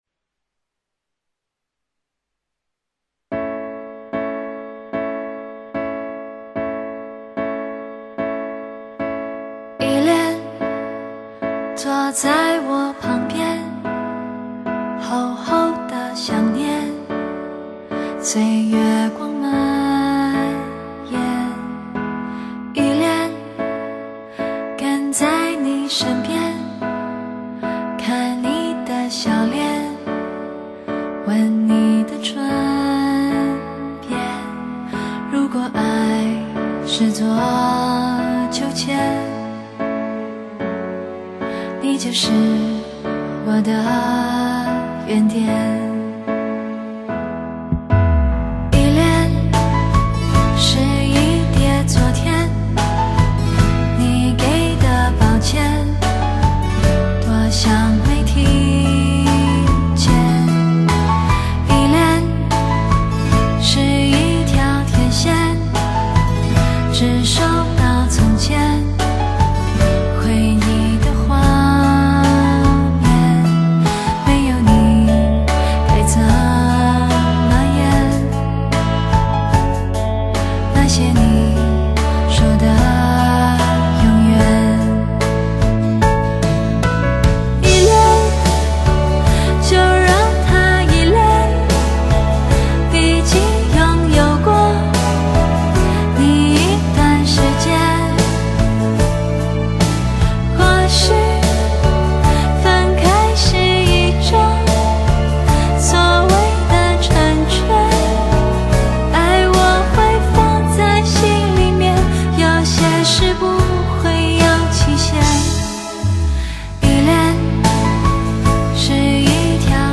整首歌曲充满了温暖感人的力量，那种缓缓流畅，娓娓道来的感觉，充分展现了她出众的演唱魅力。